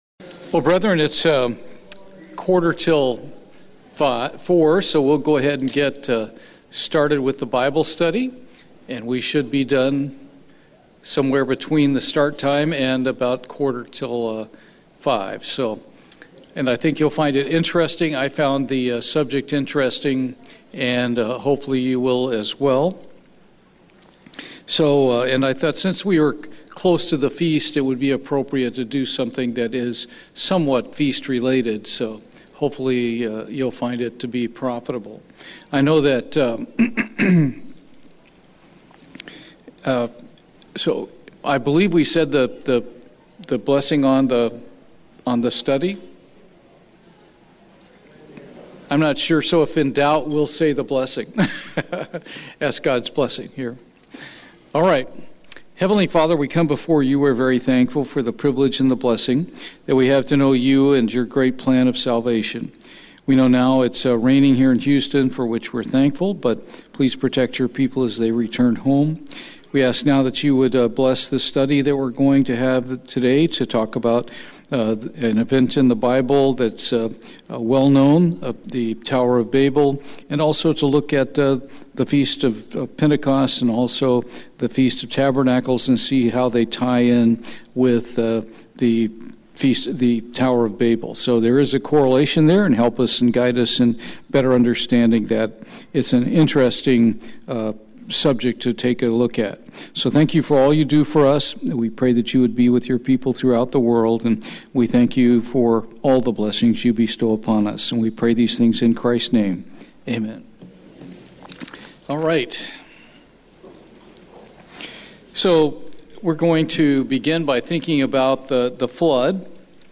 Bible study, Pentecost, Tabernacles, and the Tower of Babel
Given in Houston, TX